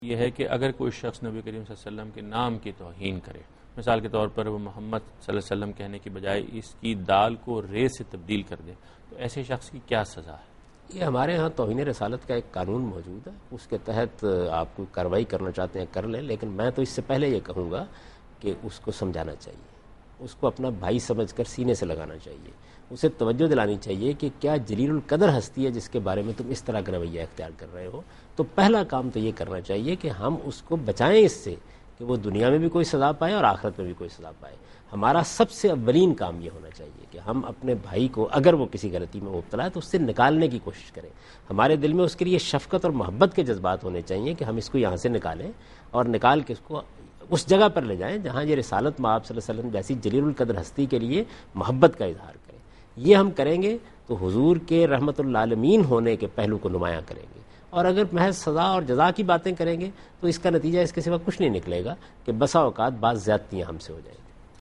Category: TV Programs / Dunya News / Deen-o-Daanish / Questions_Answers /
دنیا نیوز کے پروگرام دین و دانش میں جاوید احمد غامدی ”نبی کی بے ادبی کی سزا“ سے متعلق ایک سوال کا جواب دے رہے ہیں